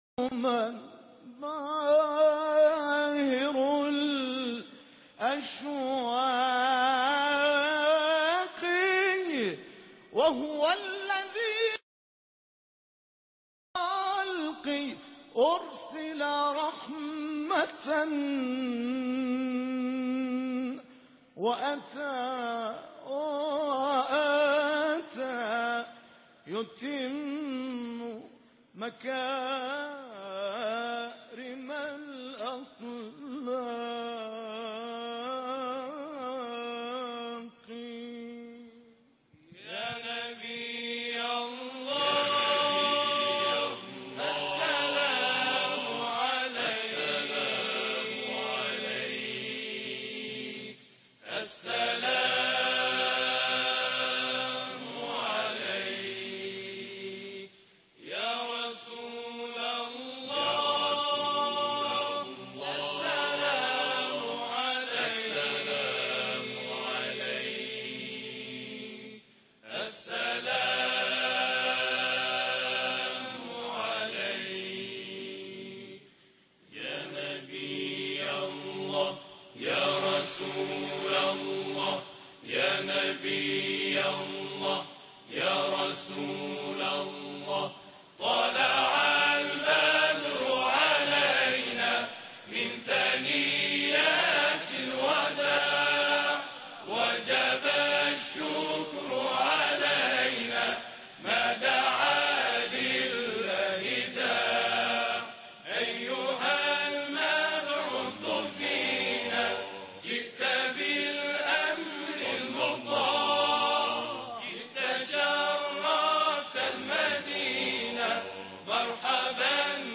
تواشیح گروه مدح النبی در محضر رهبری.mp3